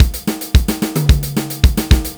Country Drums Reloaded